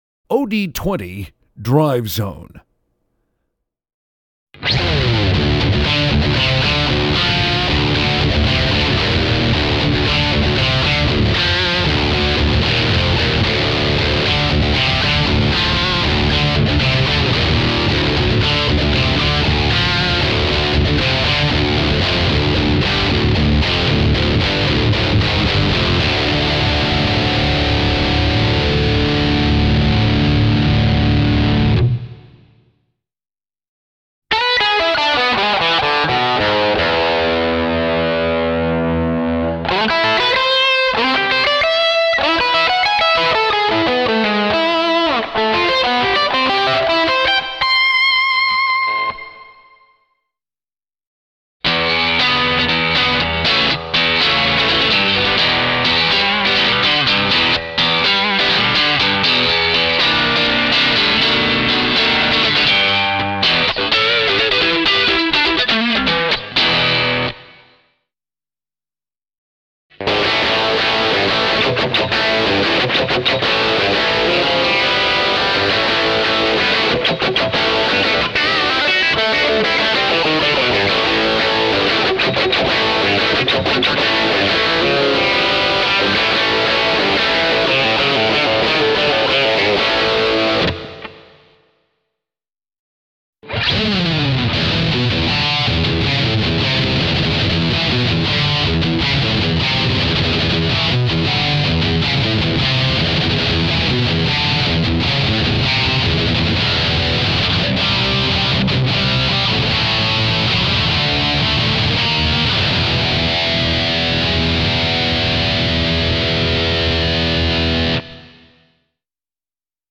BOSS OD-20 DriveZone kytarový efekt
Zvukové zkoušky